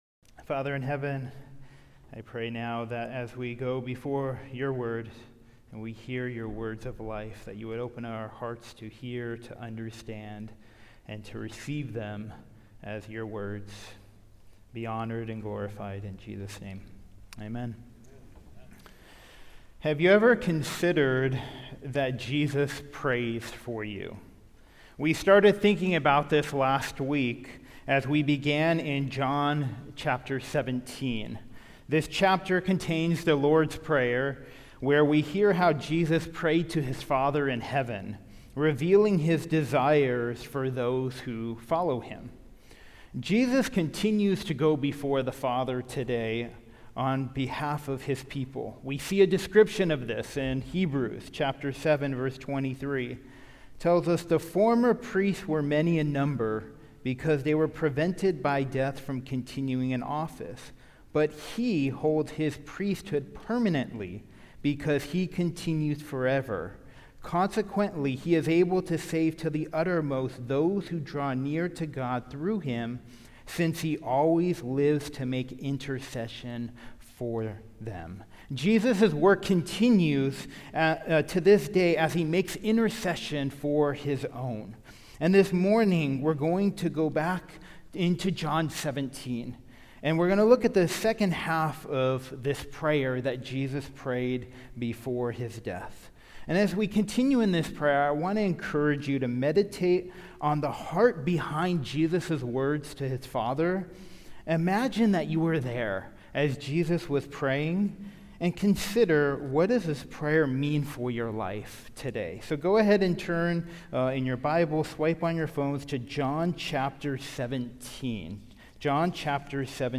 Sermon on John 17:17-26
Service Type: Sunday Service